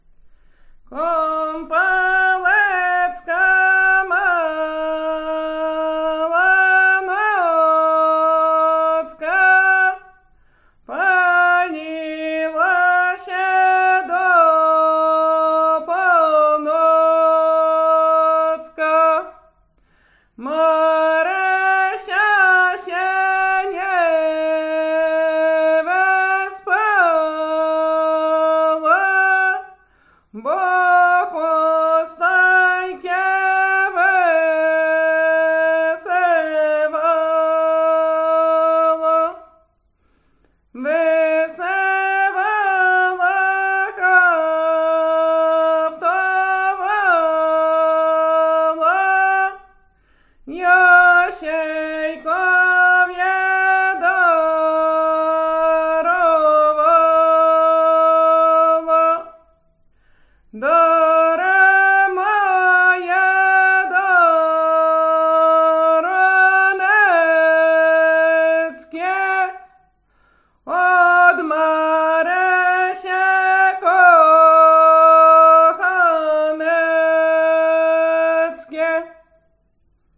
performer
Lubelszczyzna
kupalskie lato sobótkowe świętojańskie